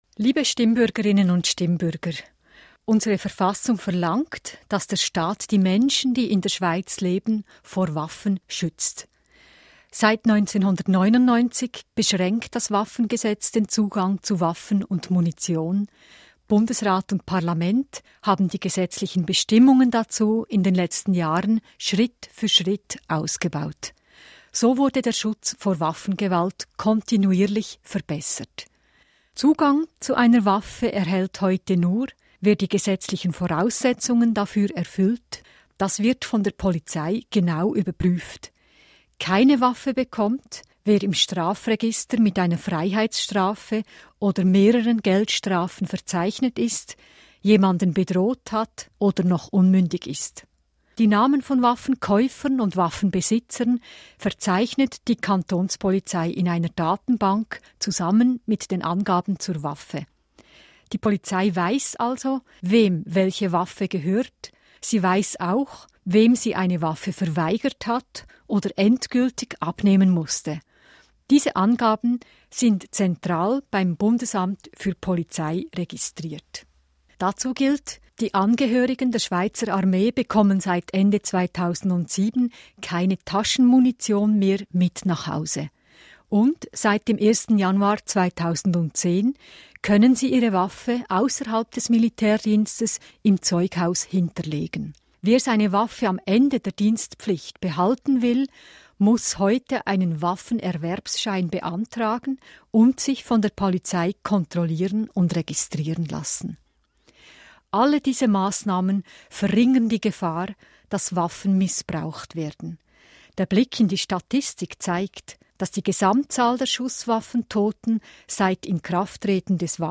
Bundesrätin Simonetta Sommaruga